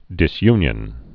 (dĭs-ynyən)